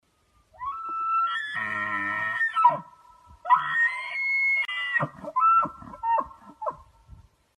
Здесь собраны натуральные записи: от мягкого перестука копыт до мощного рёва во время гона.
3. Звук оленя